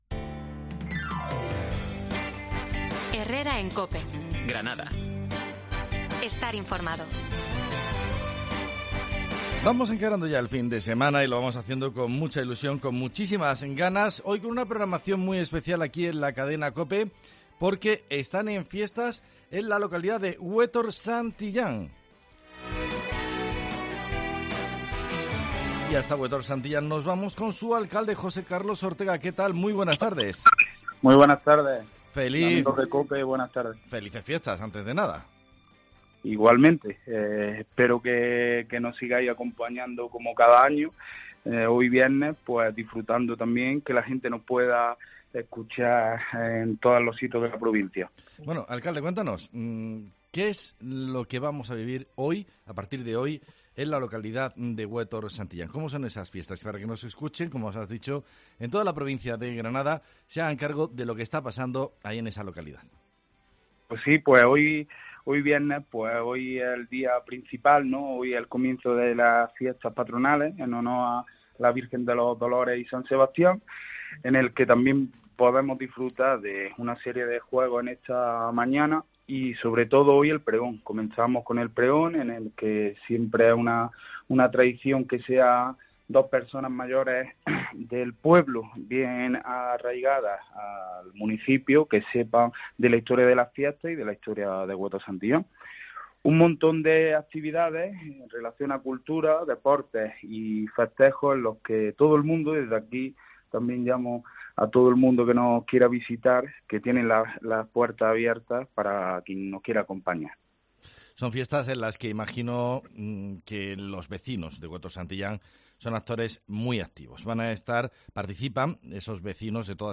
Fiestas en Huétor Santillán. Hablamos con el alcalde y la concejal de juventud